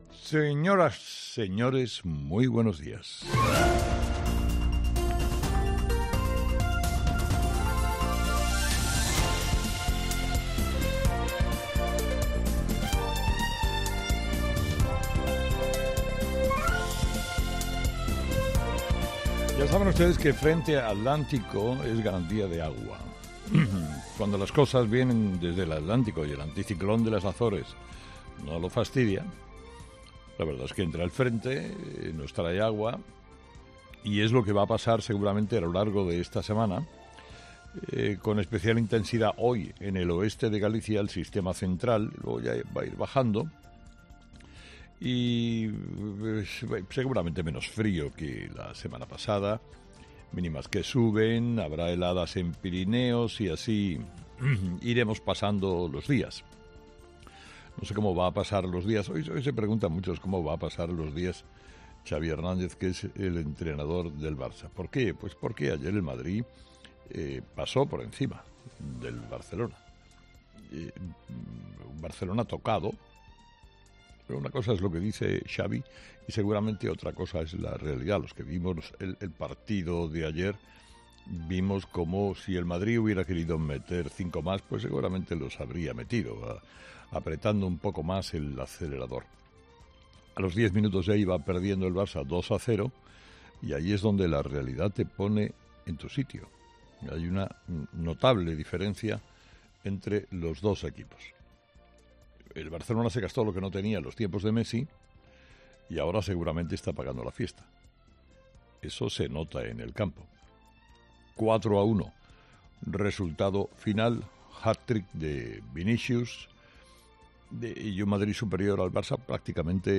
Escucha el análisis de Carlos Herrera a las 06:00 en Herrera en COPE del lunes 15 de enero